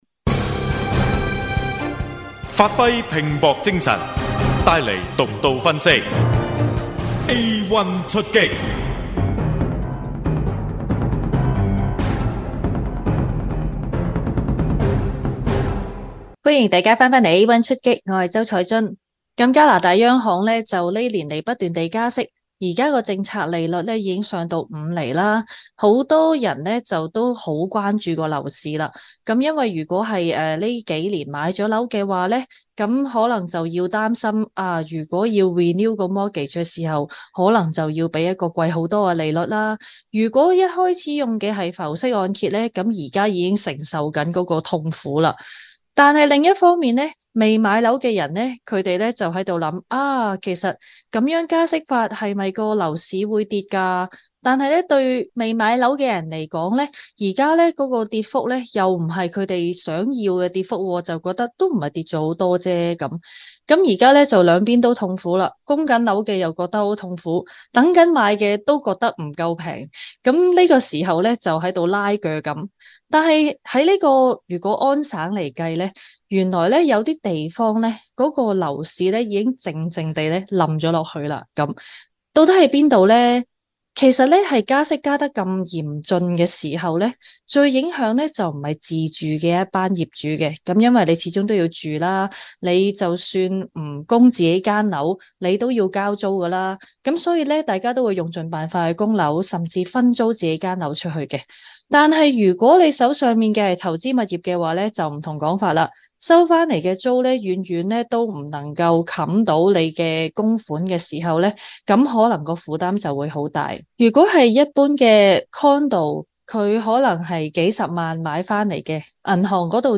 【有声新闻】央行不断加息有助楼市降温？